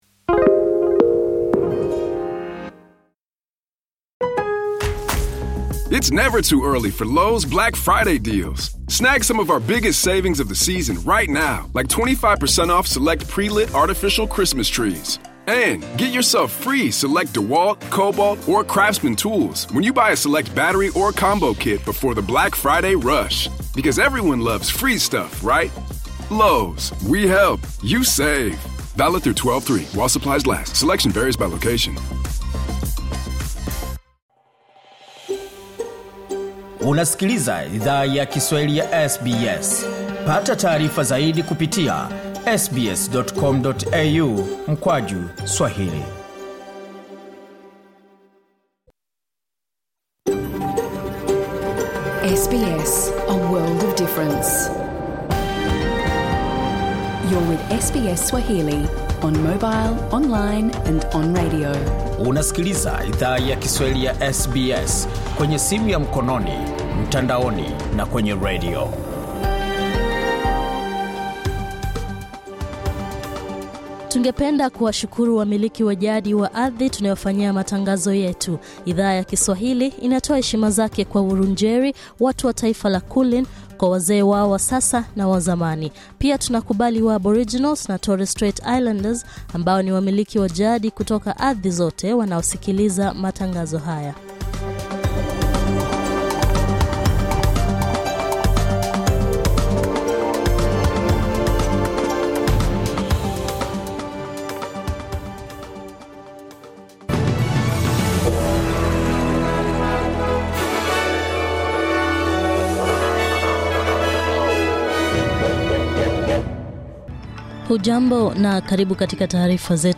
Taarifa ya habari tarehe 18 Novemba